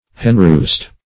Search Result for " henroost" : Wordnet 3.0 NOUN (1) 1. a roost for hens at night ; The Collaborative International Dictionary of English v.0.48: Henroost \Hen"roost`\, n. A place where hens roost.